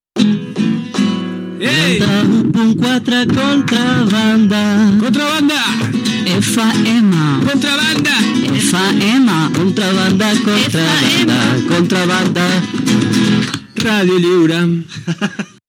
Jingle cantat